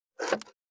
594まな板の上,包丁,ナイフ,調理音,料理,
効果音厨房/台所/レストラン/kitchen食器食材